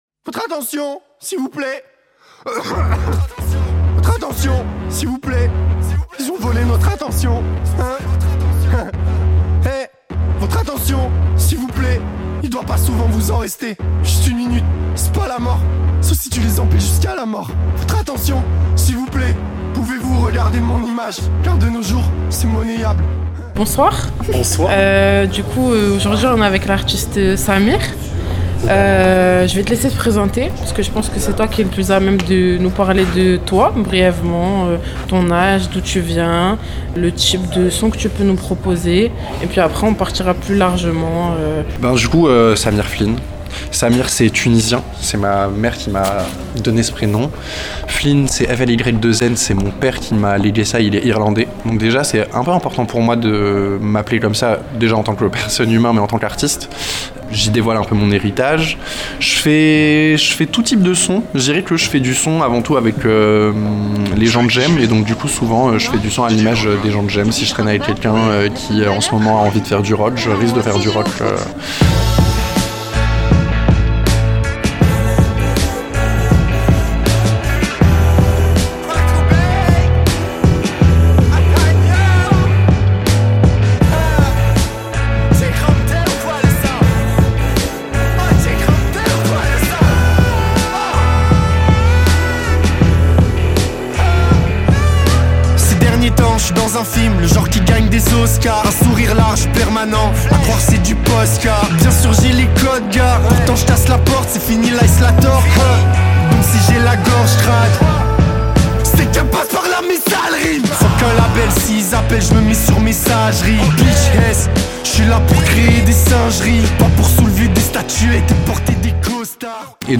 Dans cet épisode de C’est de la locale sur Radio Pulse, direction la crypte des Petits Châtelets à Alençon, au cœur du Grand Chahut.
Une interview réalisée sur place, dans l’ambiance du Grand Chahut, qui témoigne de la vitalité artistique du territoire.